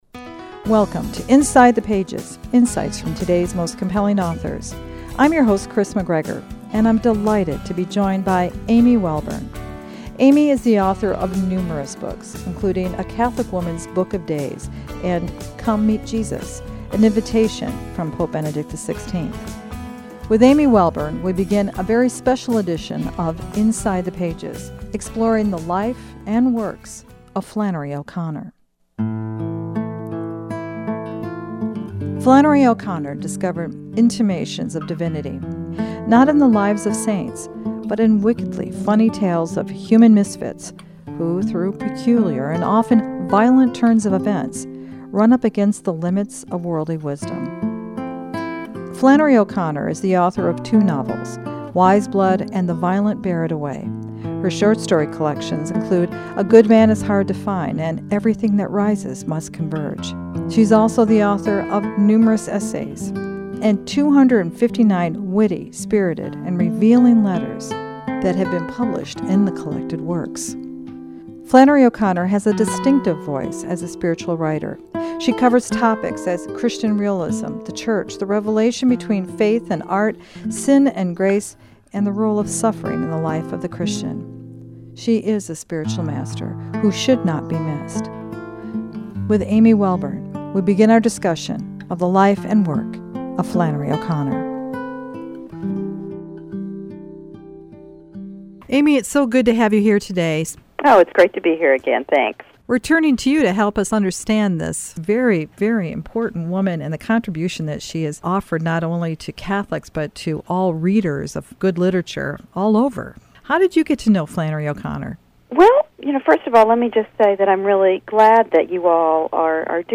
Inside the Pages” discussion